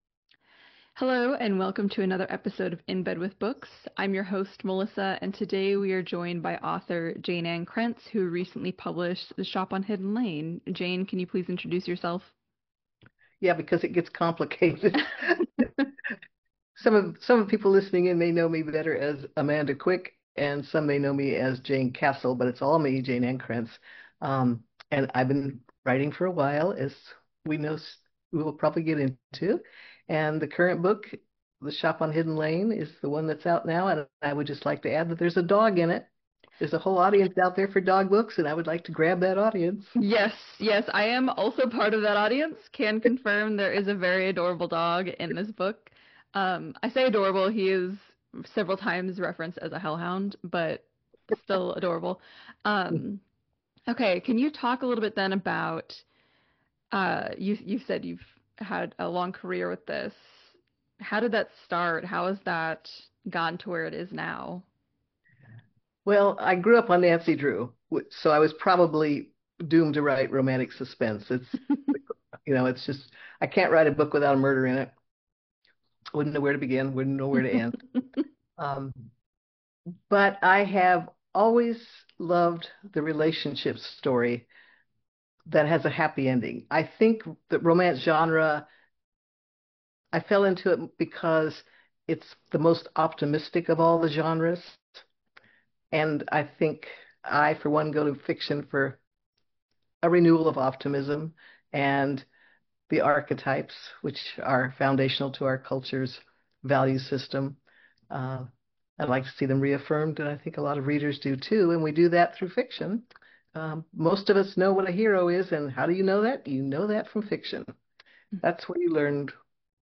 Interview with Author Jayne Ann Krentz: Powerful Women and Romantic-Suspense in "The Shop on Hidden Lane"